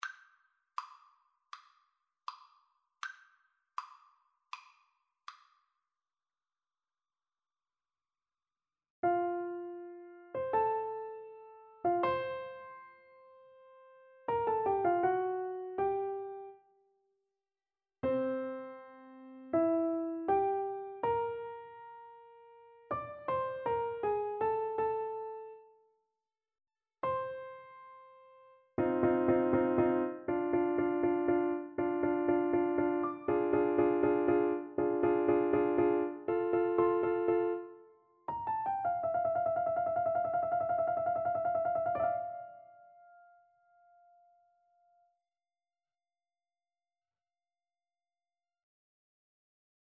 Andante
Classical (View more Classical Piano Duet Music)